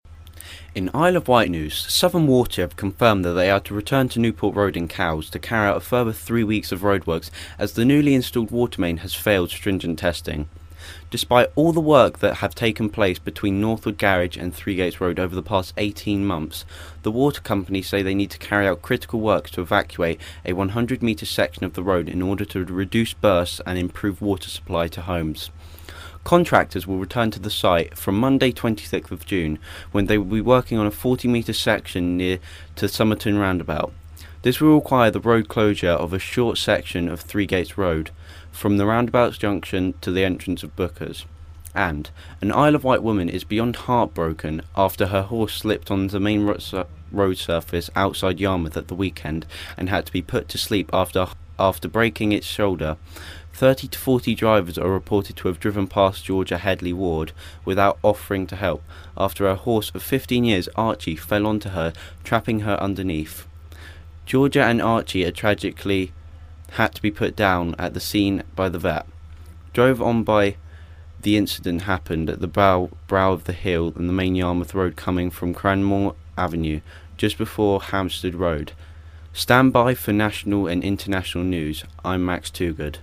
reads the news Wednesday